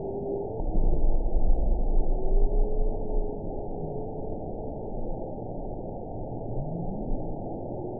event 922019 date 12/25/24 time 10:11:21 GMT (5 months, 3 weeks ago) score 8.82 location TSS-AB10 detected by nrw target species NRW annotations +NRW Spectrogram: Frequency (kHz) vs. Time (s) audio not available .wav